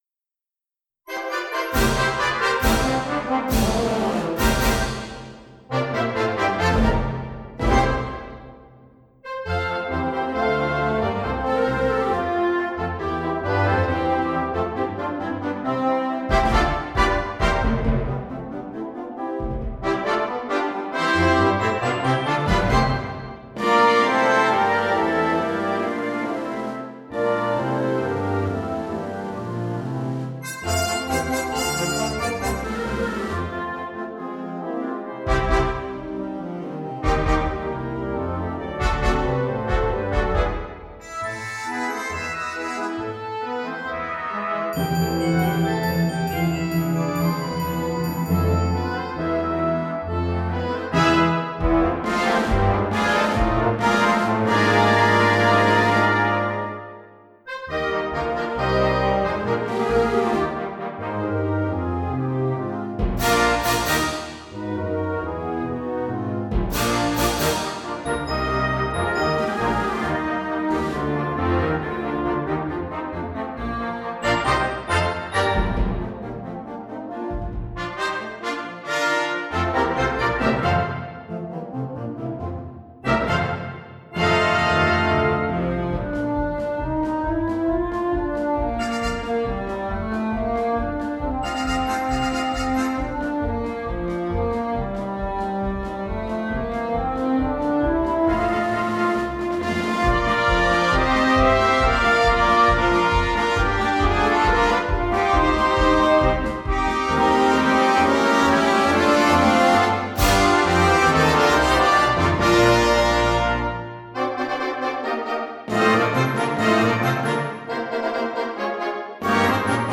Partitions pour orchestre d'harmonie.
marches